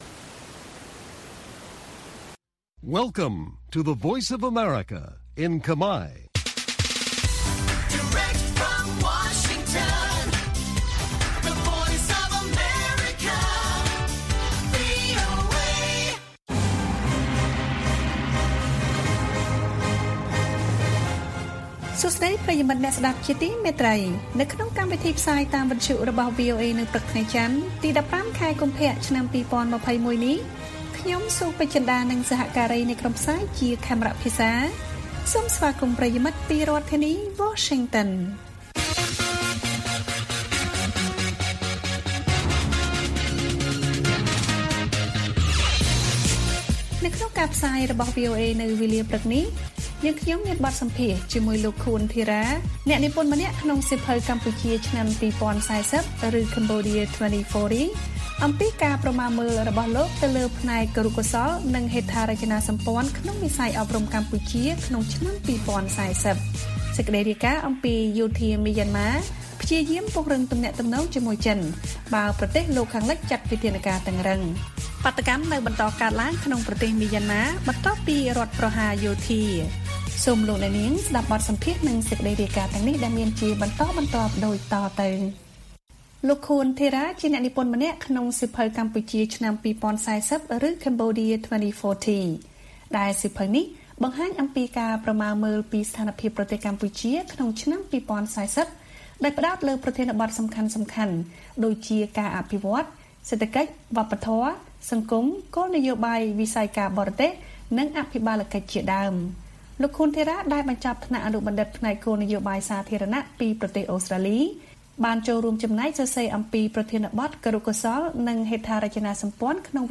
ព័ត៌មានពេលព្រឹក៖ ១៥ កុម្ភៈ ២០២១